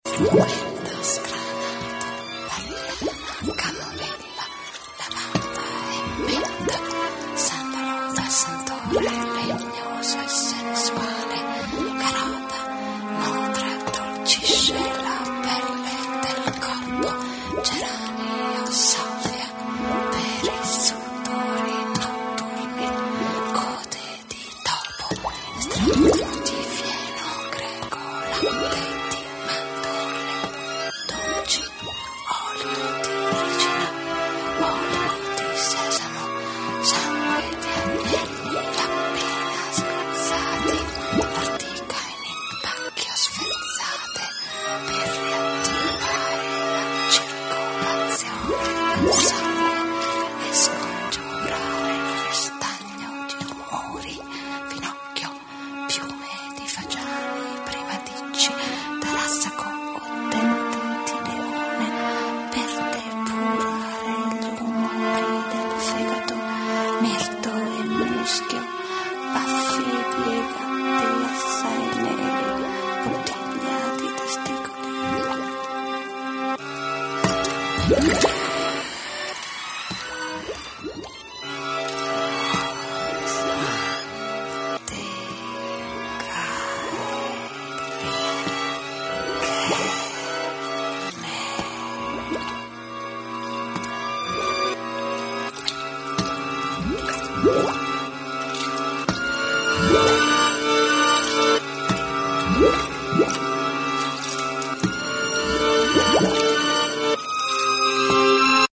The Jon Rose Web Archive, Radio Violin - Bagni Di Dolabella, RAI, Roman baths, sex slave, political scandal, Roman medical cures, radiophonic